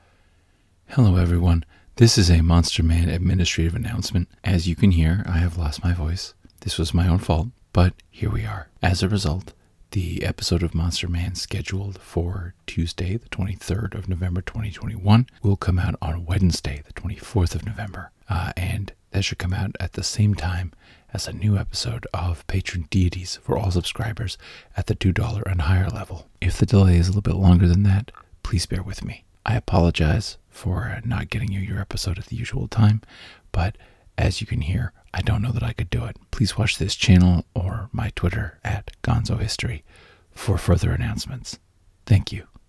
This is the perfect voice for Smooth Jazz FM's midnight to dawn shift.😁